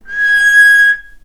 vc-G#6-mf.AIF